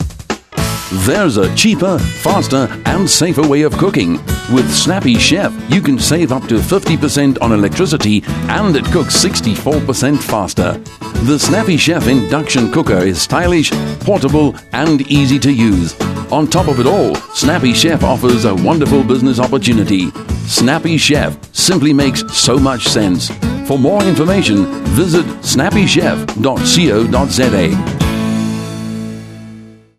Radio Ads
Snappy Chef 30 sec Spot.mp3